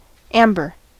amber: Wikimedia Commons US English Pronunciations
En-us-amber.WAV